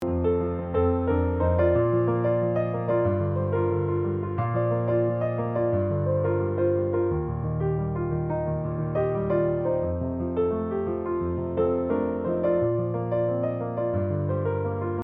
piano interpretations